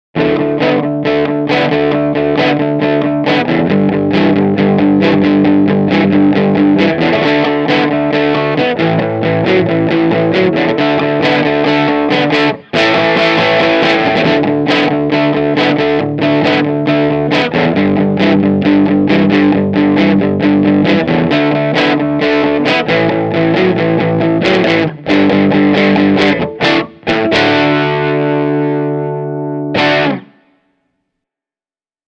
Reverb and echo have been added at the mixing stage.
Telecaster – ch 2 – drive
telecaster-ch-2-crunch.mp3